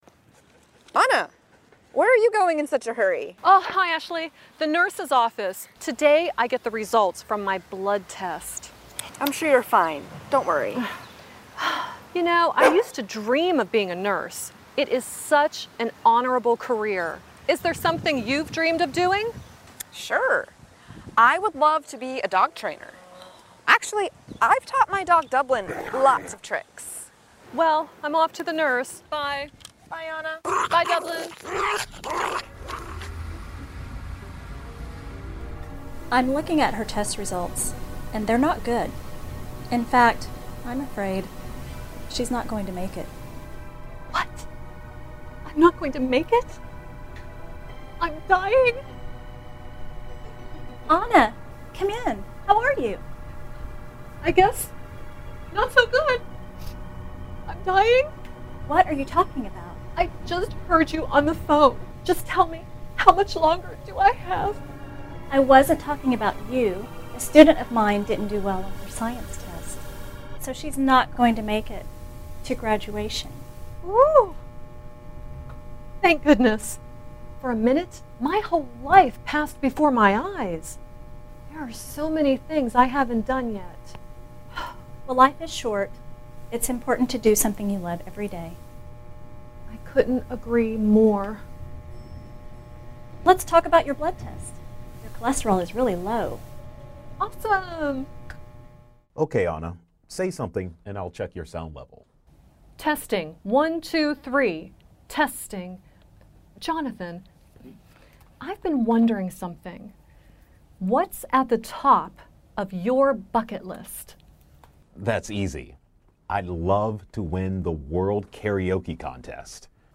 Hội thoại (Conversation)